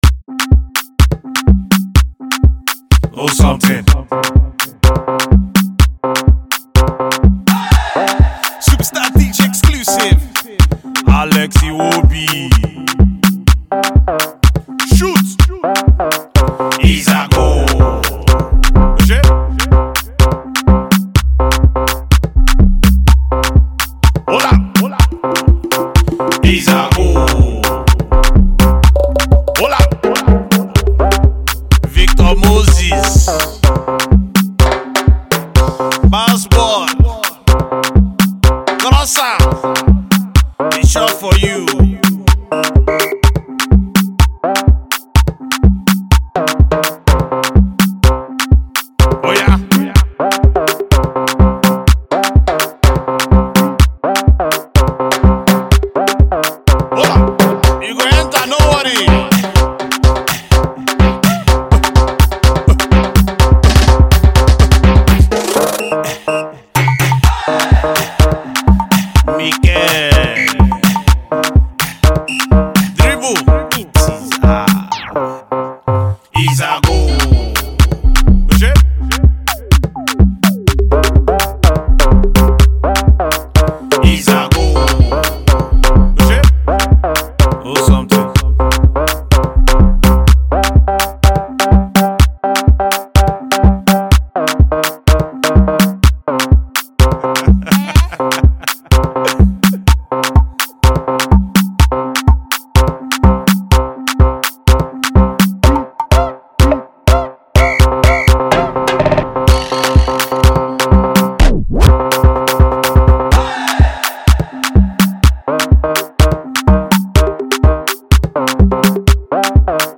potential club banger